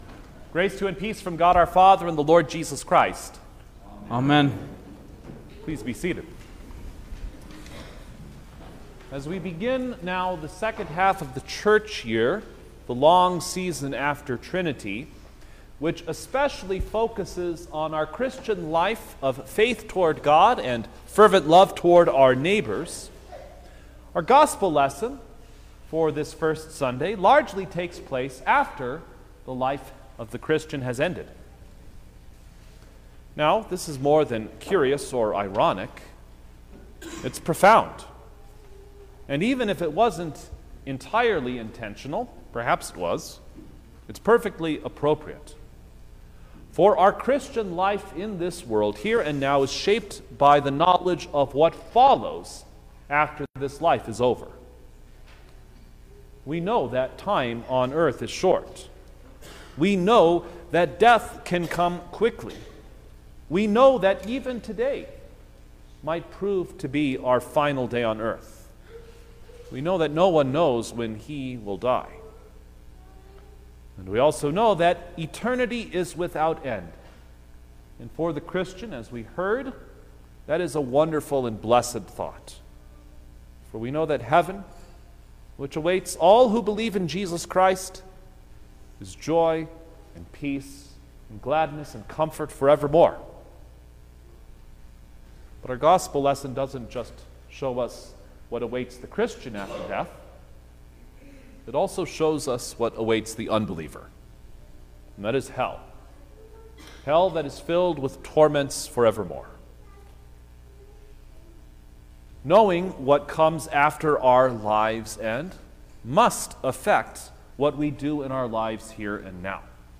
June-2_2024_First-Sunday-after-Trinity_Sermon-Stereo.mp3